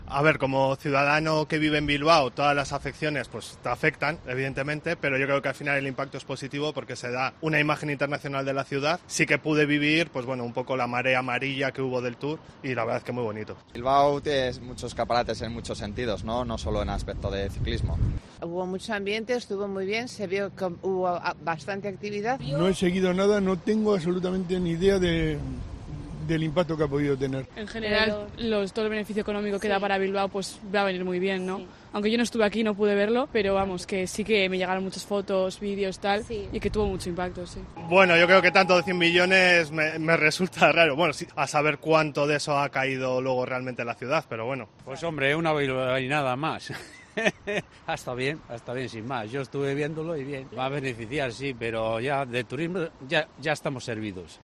En COPE Euskadi hemos preguntado a los bilbaínos cómo vivieron y les afectó la marea amarilla a su paso por Euskadi
El debate sobre el impacto del Tour de Francia ha llegado a la calle.